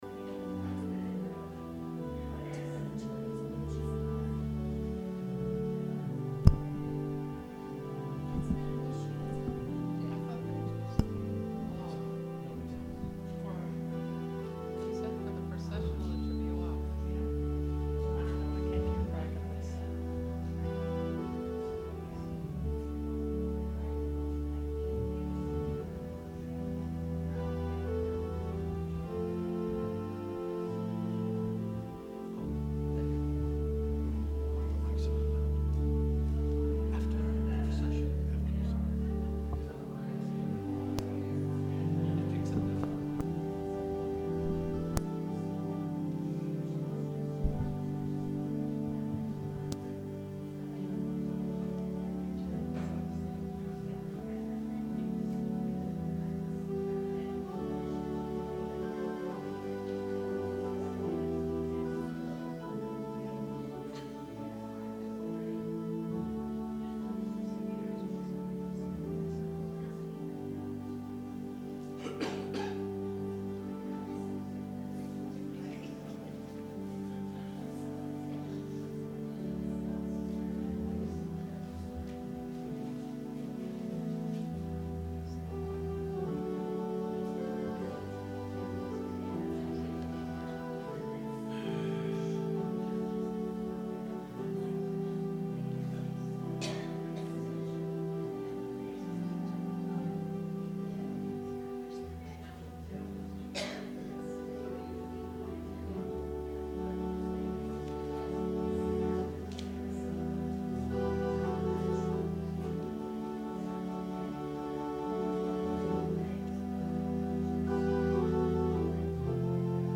Sermon – February 3, 2019
advent-sermon-february-3-2019.mp3